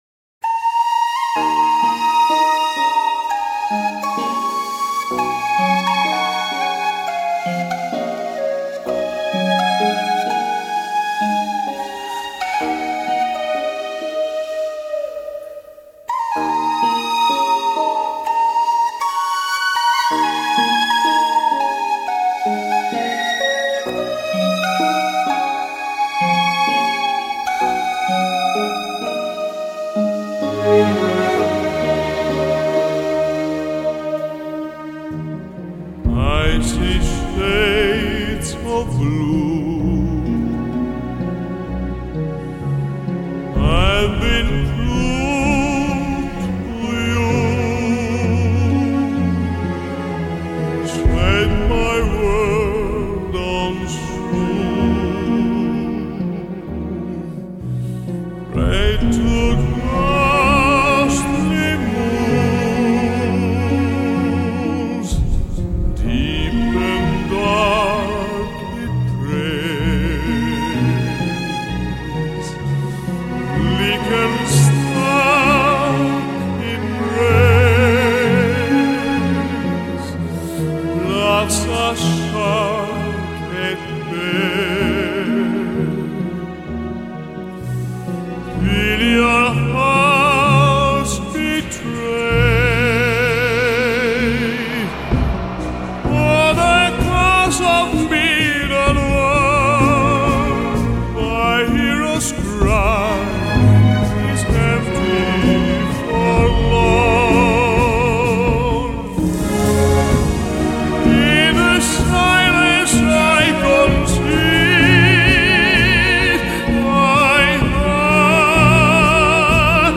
饱满嘹亮的漂亮男高音音色
带著浓厚义大利风味的流行歌剧